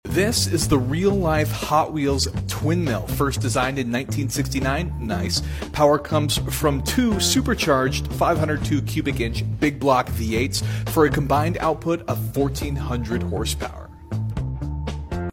1400hp Real Life 1969 Twin Mill From Hot Wheels